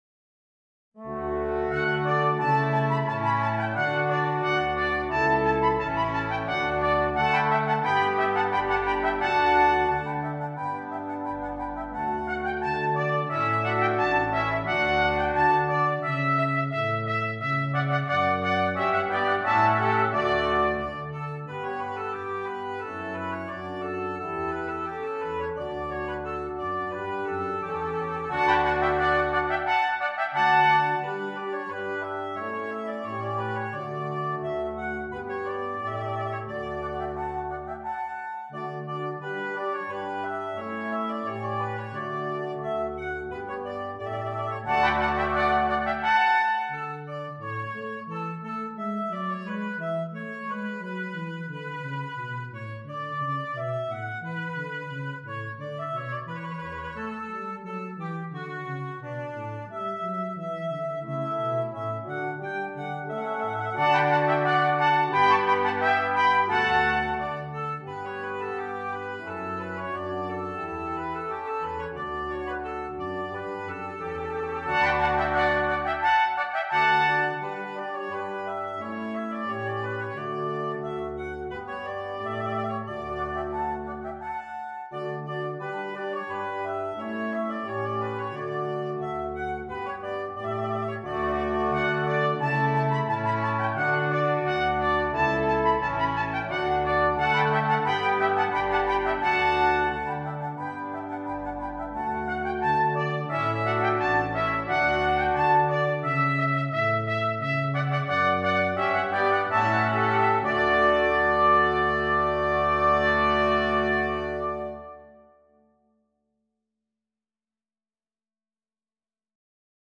Brass Quintet and Soprano